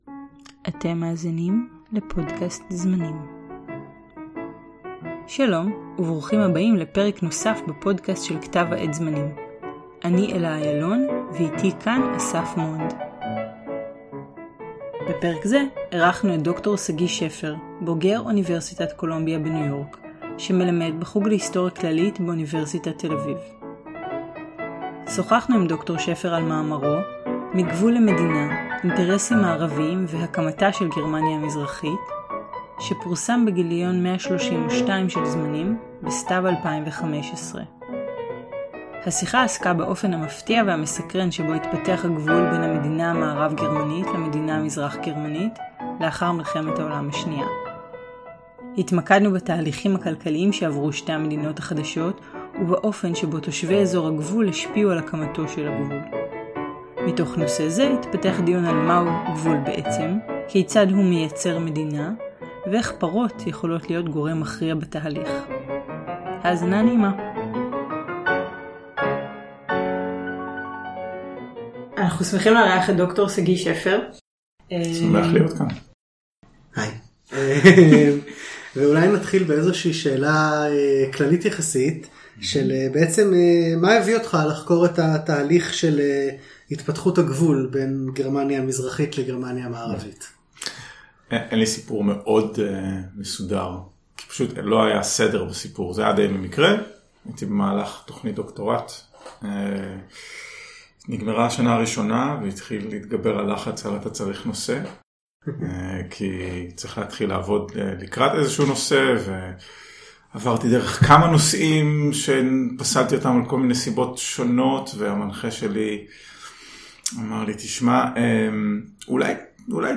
הפרק הוקלט באוניברסיטת תל אביב.